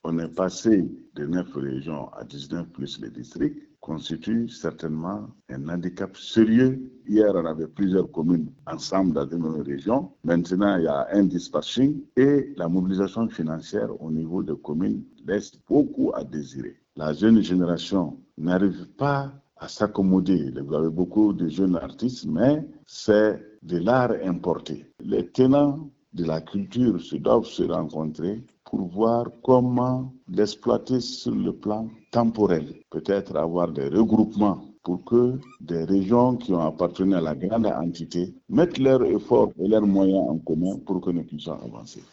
REACTION_ACTEUR_CULTUREL_FR.mp3